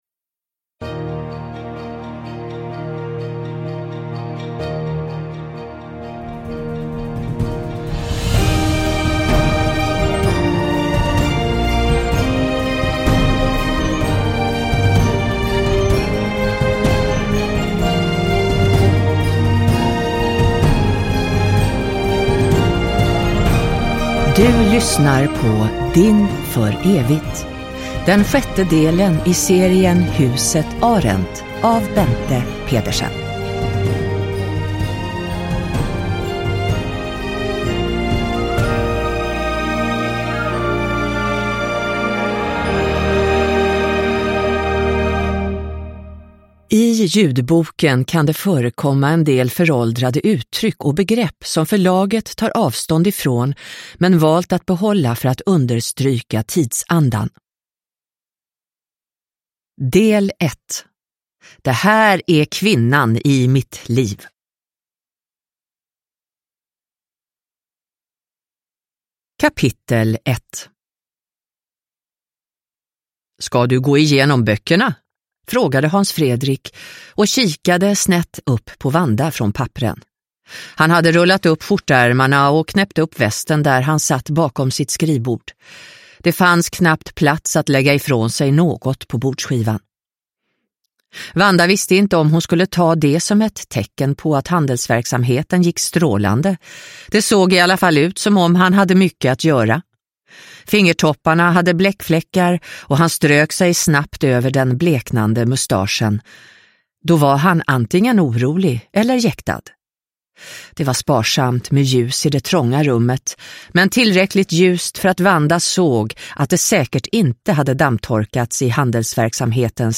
Din för evigt – Ljudbok – Laddas ner